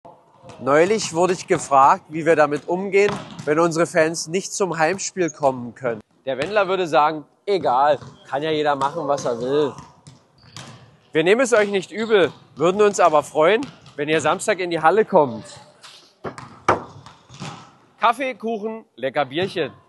Die Zuschauertribüne war gut besucht und ihr habt die Halle zum Beben gebracht.
Video zum Heimspieltag 21.03.26